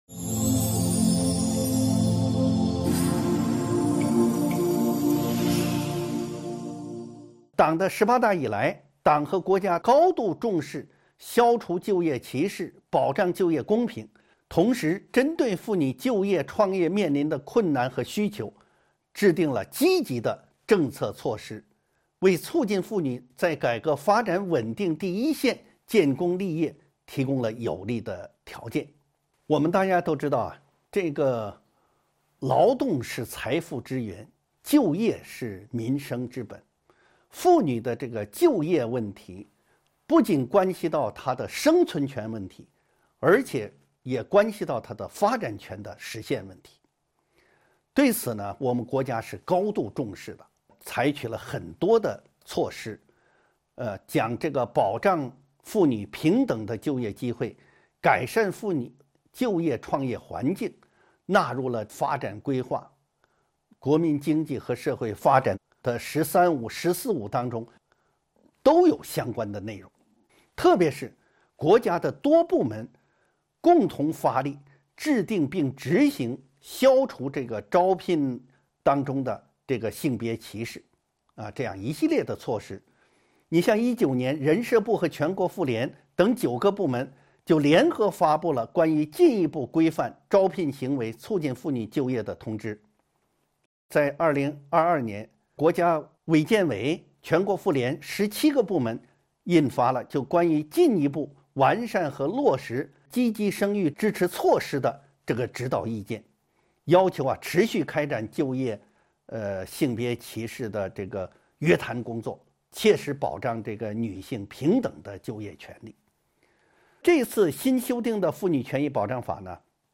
音频微课：《中华人民共和国妇女权益保障法》16.就业性别歧视的认定及其防治